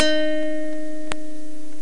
Acoustic Note Sound Effect
Download a high-quality acoustic note sound effect.
acoustic-note.mp3